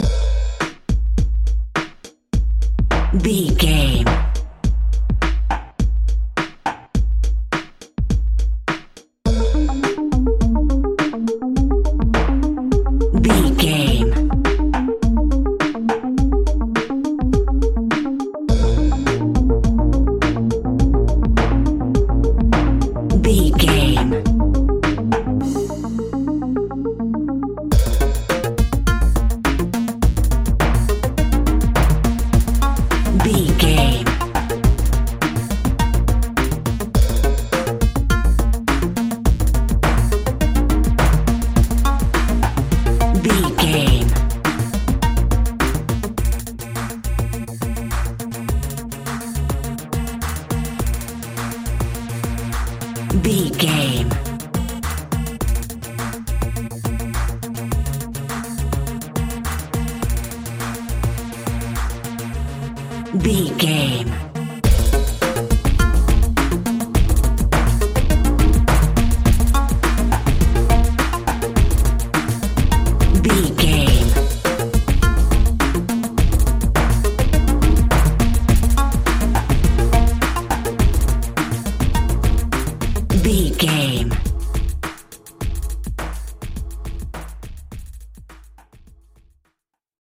Aeolian/Minor
B♭
energetic
futuristic
hypnotic
drum machine
synthesiser
trance
synth lead
synth bass